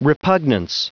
Prononciation du mot repugnance en anglais (fichier audio)
repugnance.wav